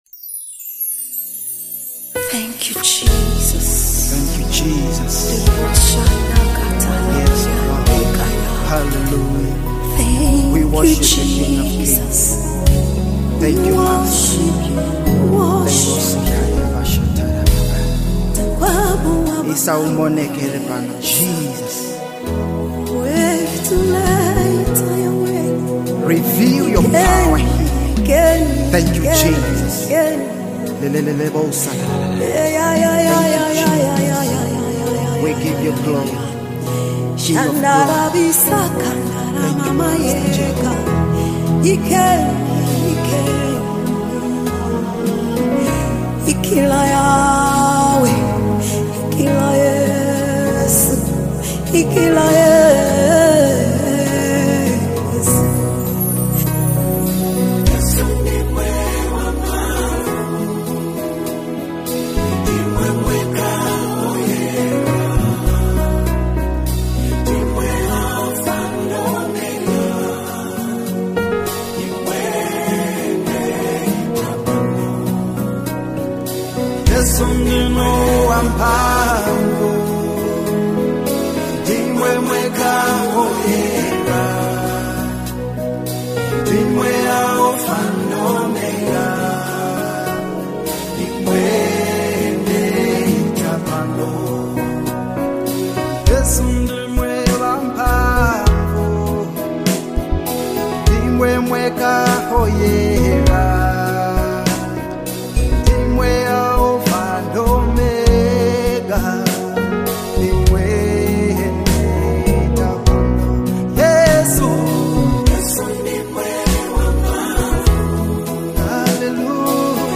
Zambian Worship Anthem | Latest Zambian Gospel Music